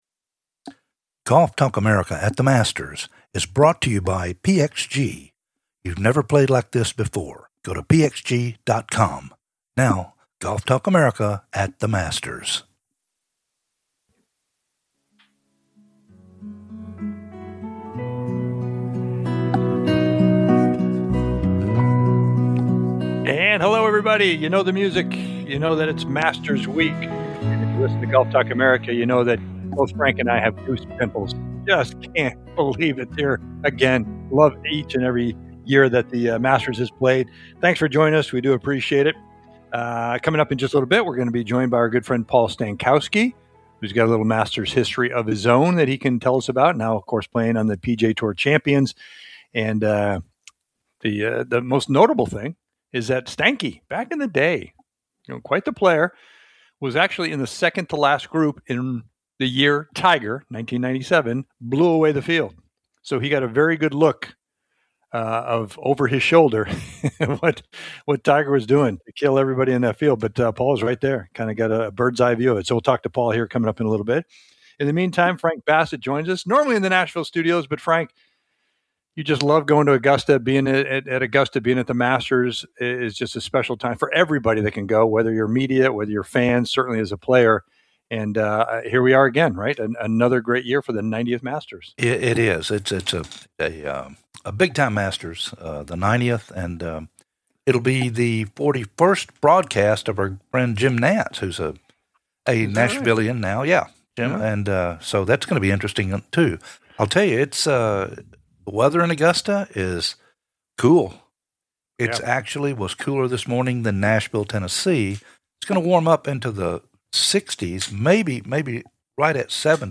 "LIVE" FROM THE MASTERS WITH PAUL STANKOWSKI